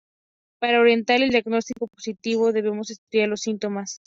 Read more Adj Verb positivar to develop (a photo) Read more Opposite of negativo Frequency B2 Hyphenated as po‧si‧ti‧vo Pronounced as (IPA) /posiˈtibo/ Etymology From Latin positīvus.